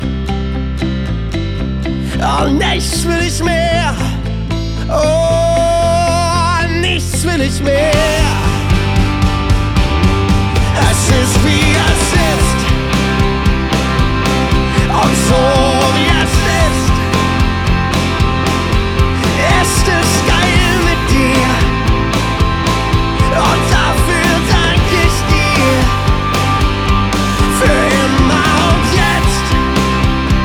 Gesang, Gitarre, Klavier und Backings
eine tief emotionale Atmosphäre